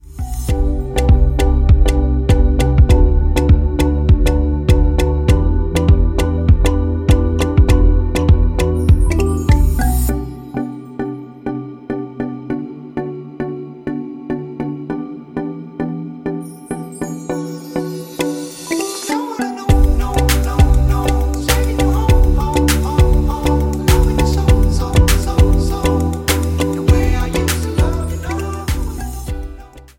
Em
MPEG 1 Layer 3 (Stereo)
Backing track Karaoke
Pop, 2010s